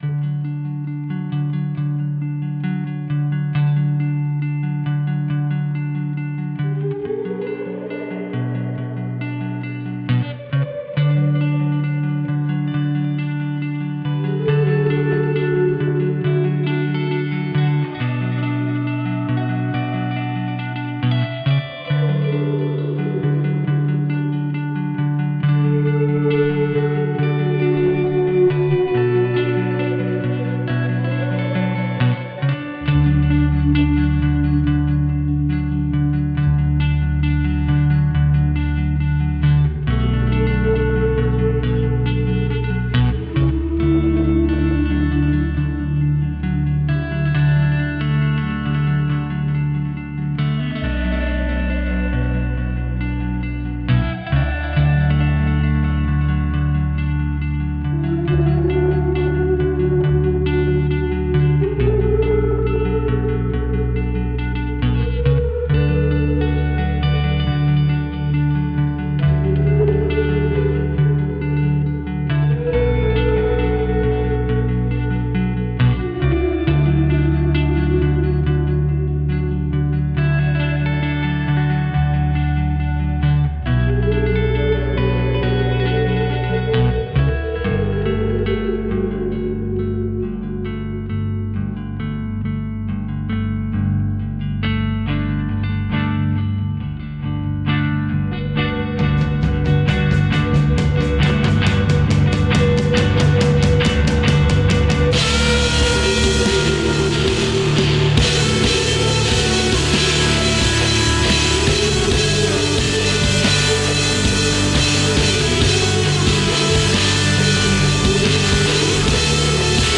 They love the whale sound in the background, and i know I used some sort of Flanger/Delay/Reverb run through my guitar using a slide and a Boss Me-50 with some ableton audio effects dropped on top, but for the life of me I can't remember what I used. I do remember I didn't use a volume knob or pedal to create the slow attack effect. Any help is appreciated, and you can laugh at the shoddy recording quality.